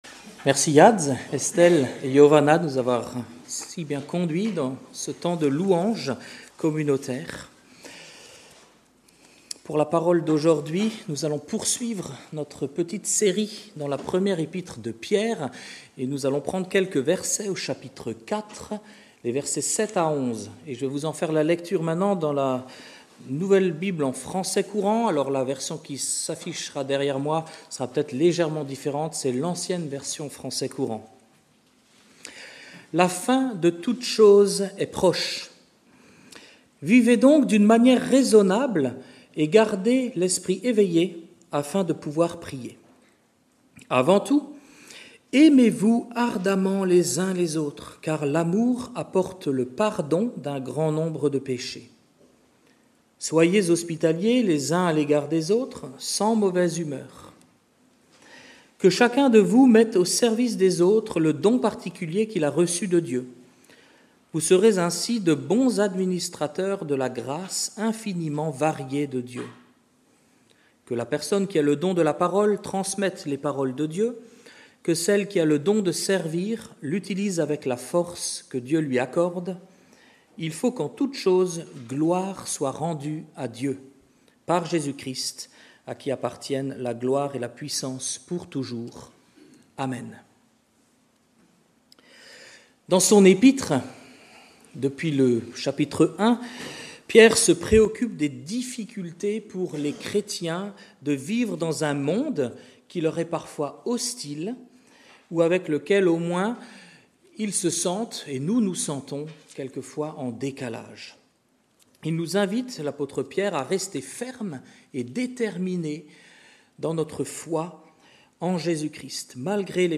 Culte du dimanche 10 août 2025 – Église de La Bonne Nouvelle